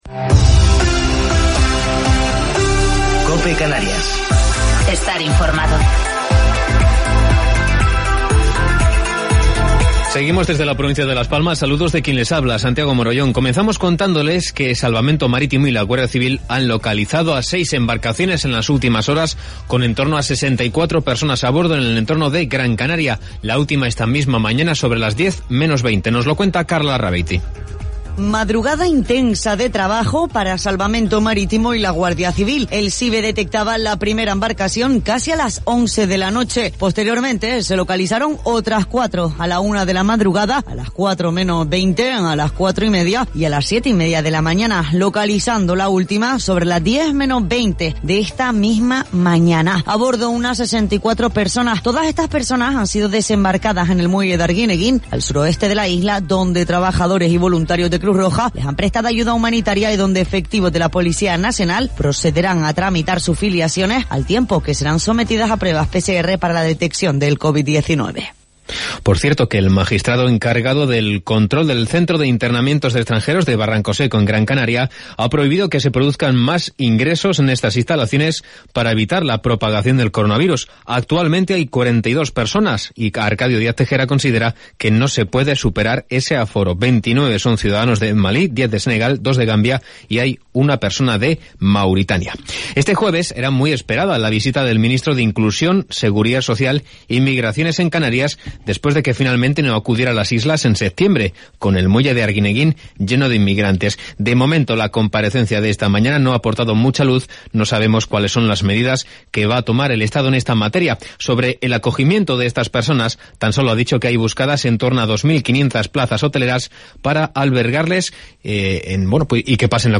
Informativo local 8 de Octubre del 2020